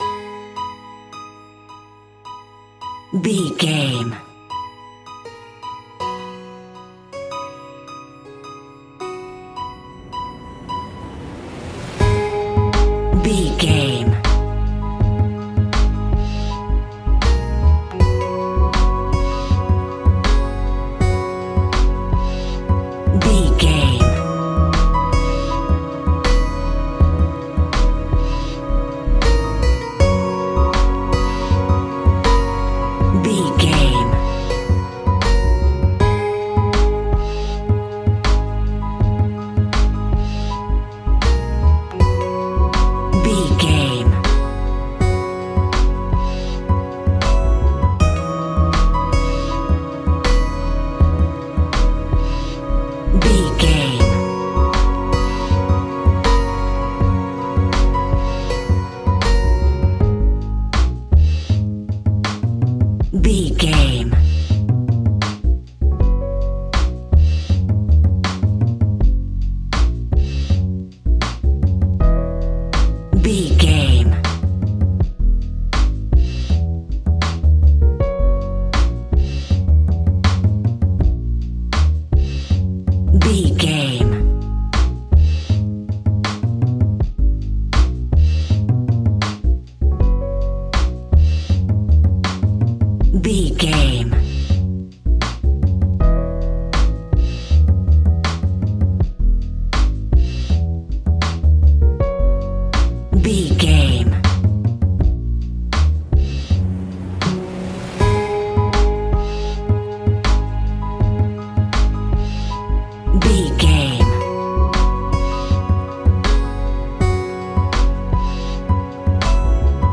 Ionian/Major
laid back
Lounge
sparse
new age
chilled electronica
ambient